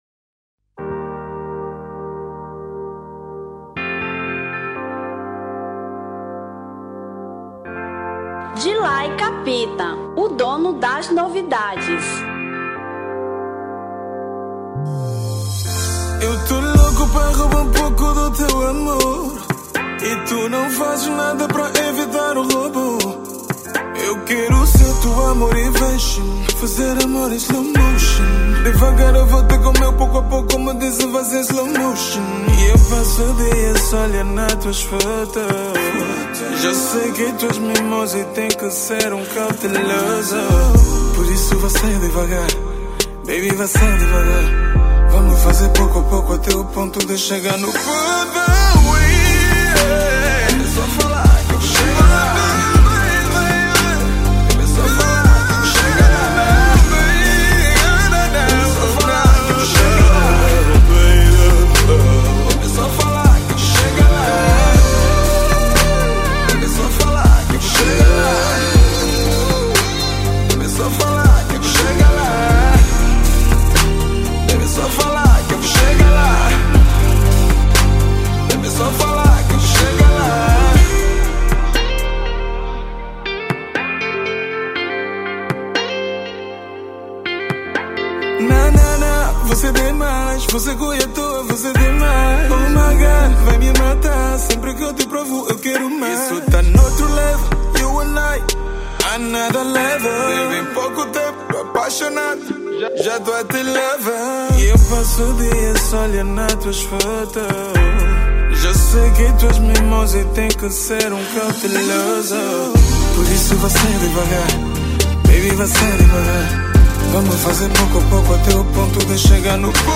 Kizomba 2017